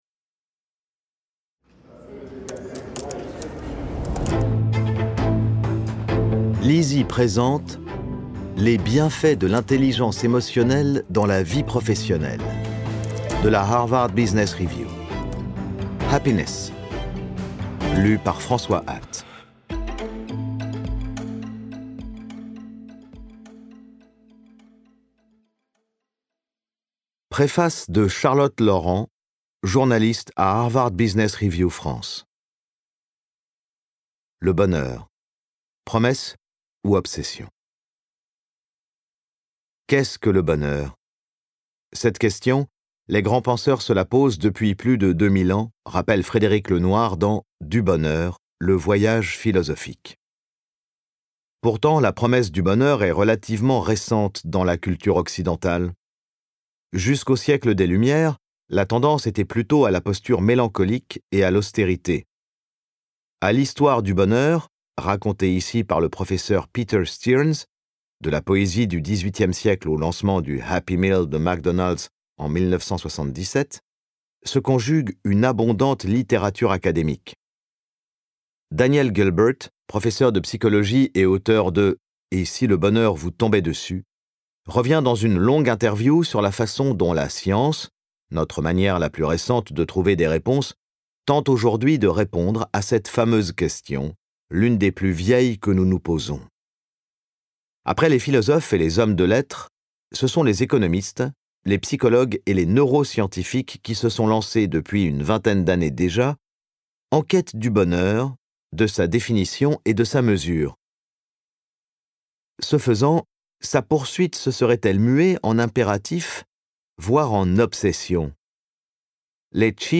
Ce livre audio fait partie de la collection Les Bienfaits de l'intelligence émotionnelle dans la vie professionnelle.